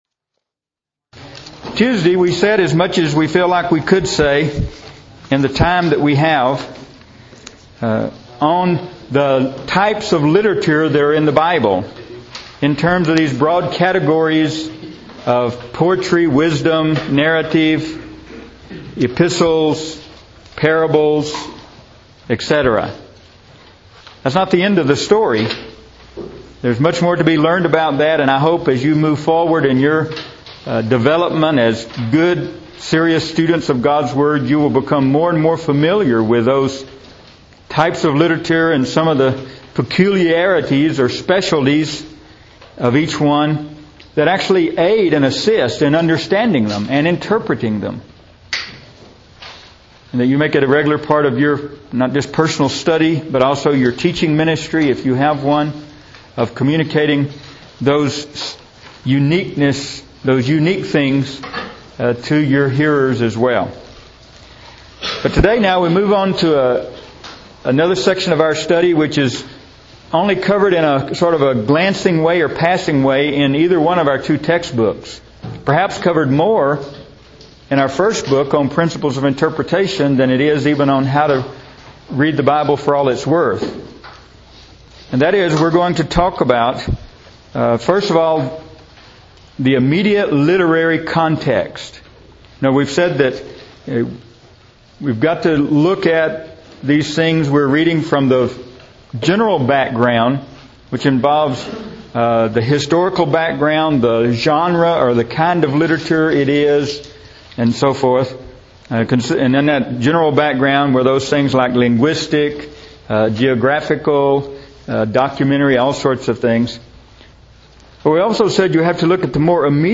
Lesson 1 Lecture Audio, Part I
This course was taught in the Winter Quarter, 2008 at the Sunset International Bible Institute.